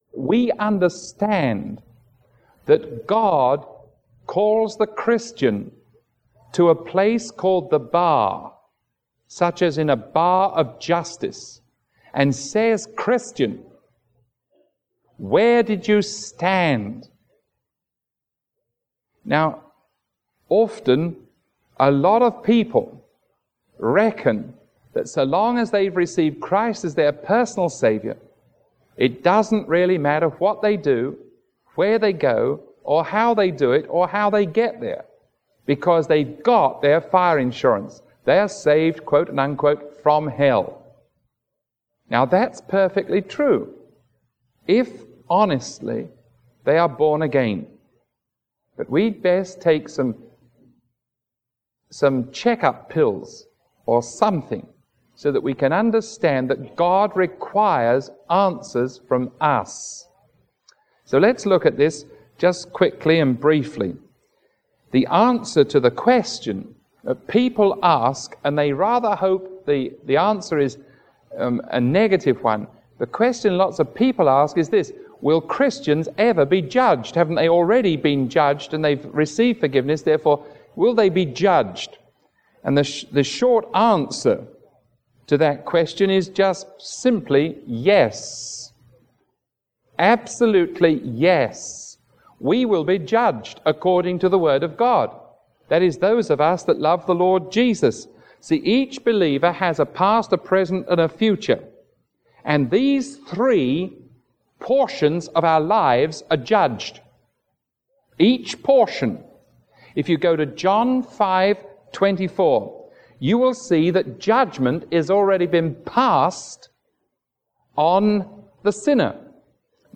Sermon 0418A recorded on September 16